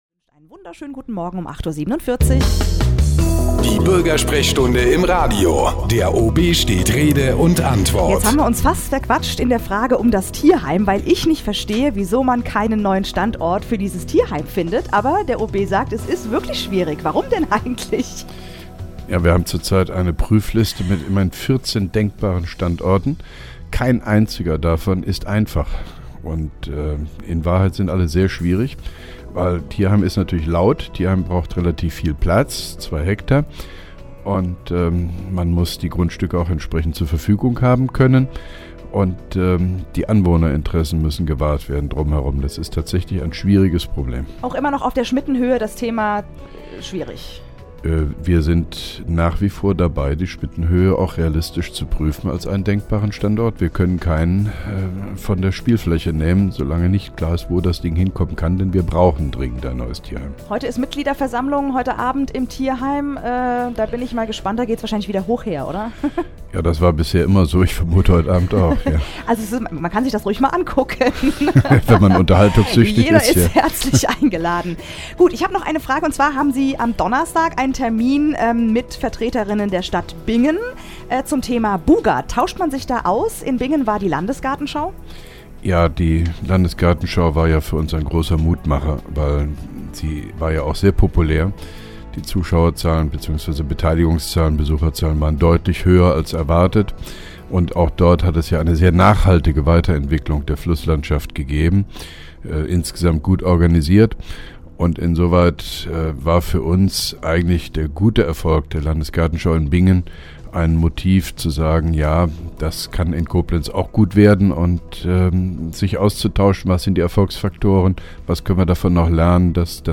Interviews/Gespräche